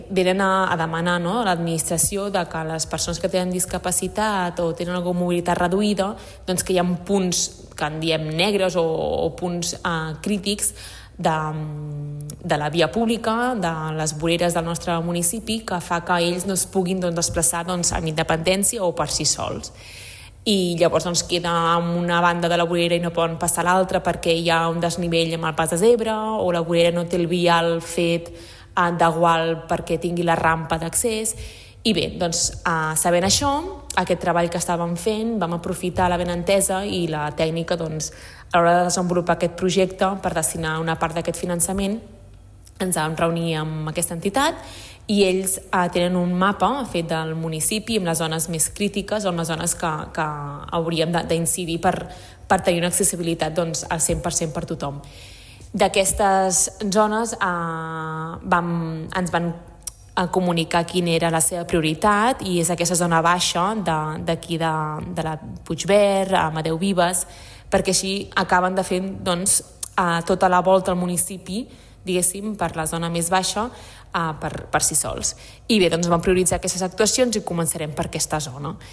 Segons explica la regidora d’obres i serveis, Bàrbara Vergés a través d’una subvenció de la Diputació de Barcelona per reparació de ferm i millora de l’accessibilitat, s’ha acordat destinar els diners per millorar aquests vials, on el grup Mifas (entitat que treballa per la inclusió social de les persones amb discapacitat) havia detectat deficiències.